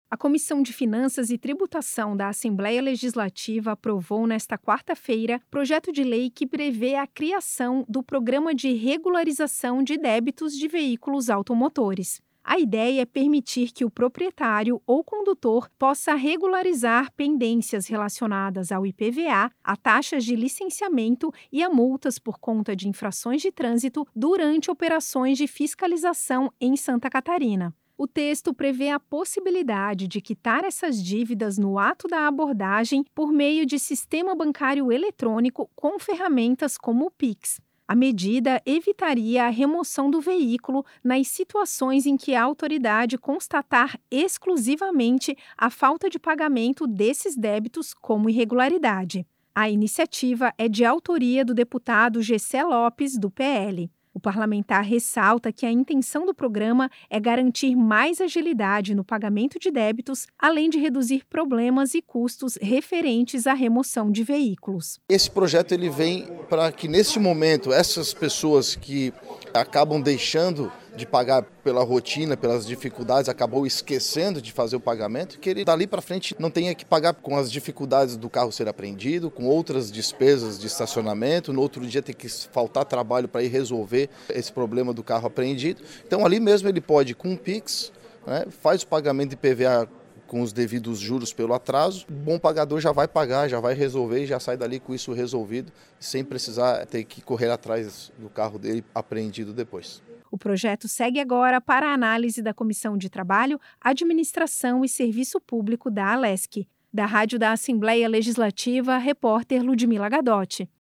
Entrevista com: